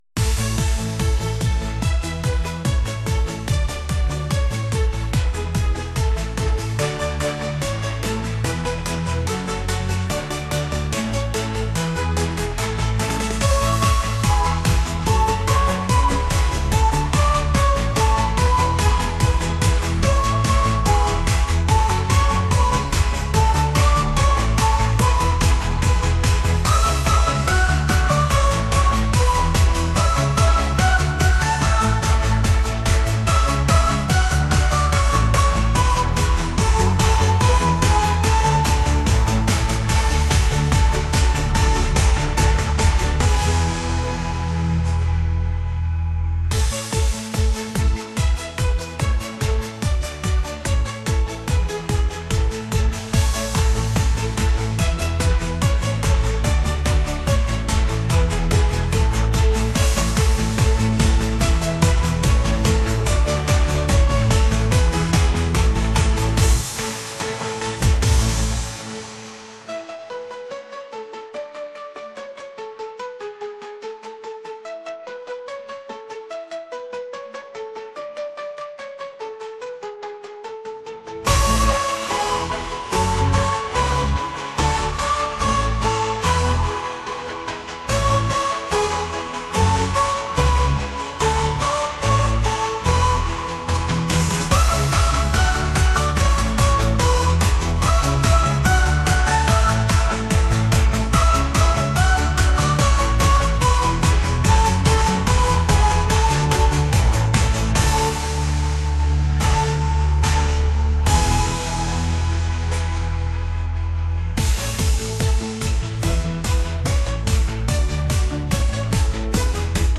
pop | cinematic | uplifting